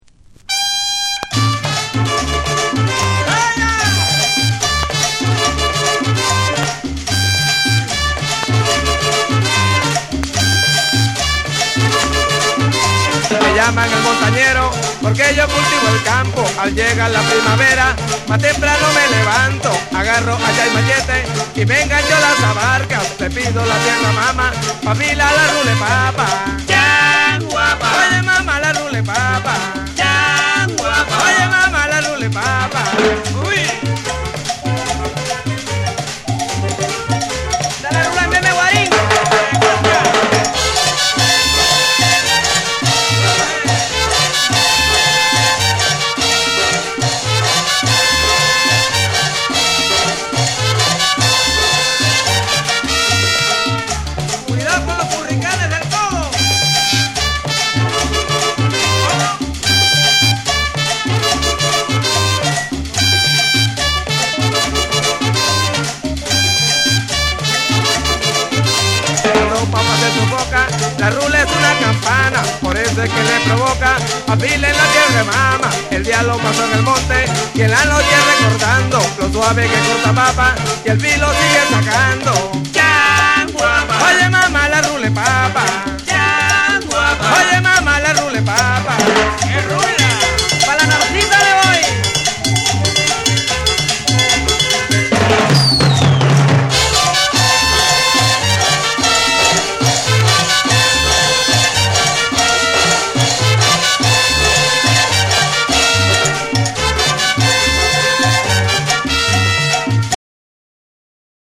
陽気でカラフルなリズム、伸びやかなホーンやギターが絡み合うトロピカル・サウンドが全編を彩る一枚。
WORLD